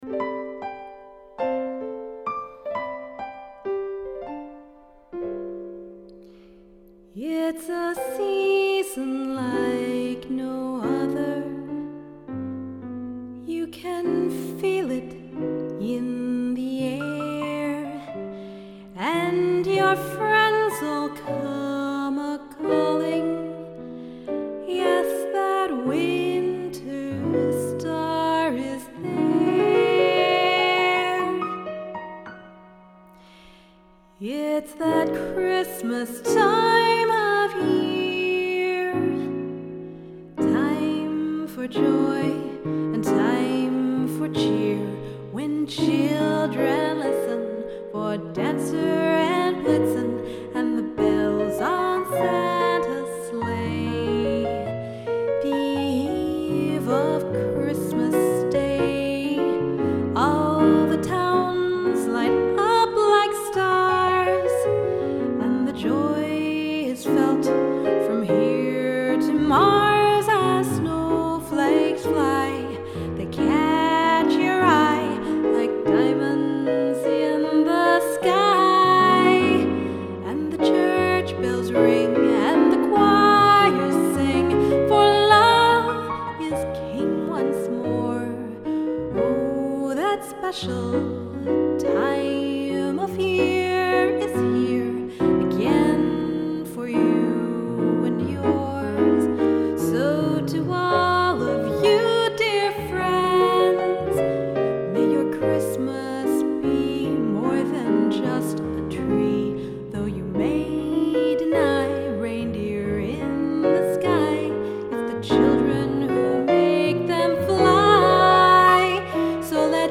keys
vocals